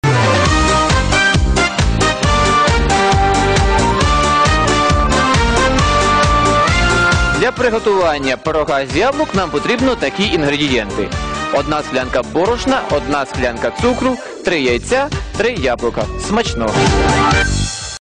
Режиссерский пирог с яблоками) АУДИО-ДОРОЖКА ИЗ ЭФИРНОЙ ТЕЛЕПРОГРАММЫ